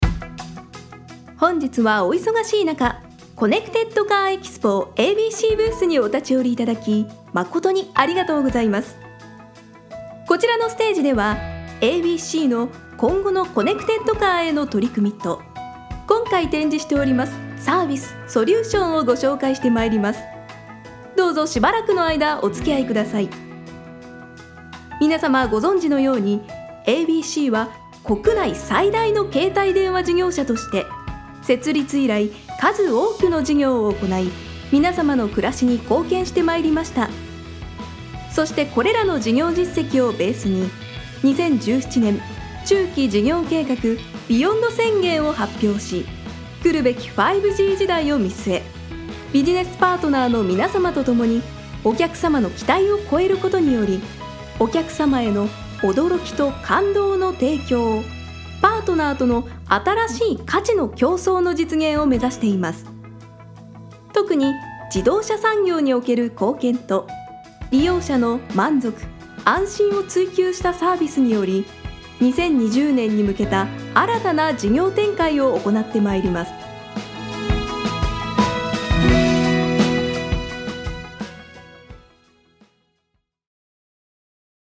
ナレーター｜MC｜